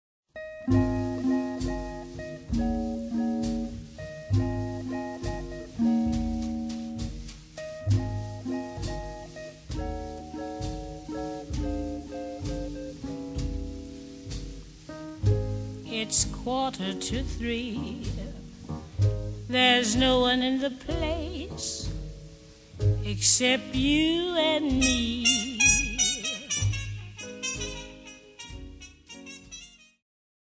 CD2の(12)-(20)はボーナス･トラックで、1957年7月、ニューポート･ジャズ祭でのステージの模様を収録。